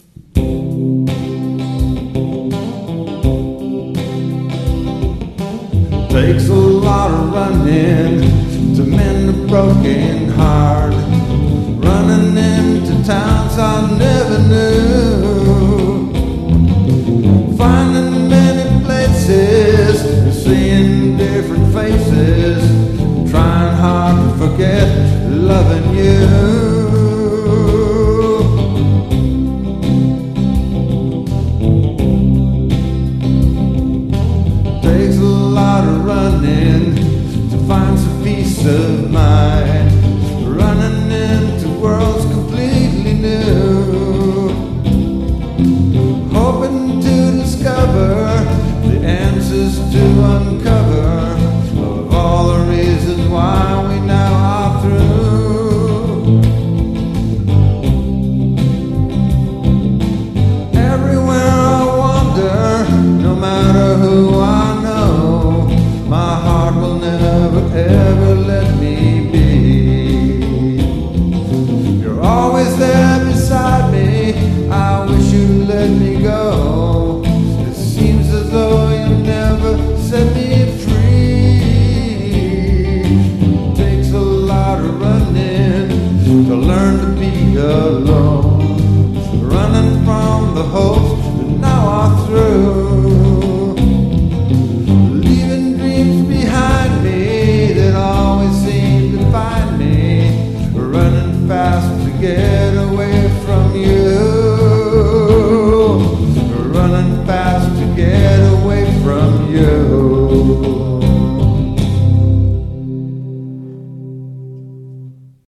(Style: Country)